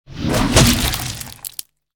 dragonclaw.ogg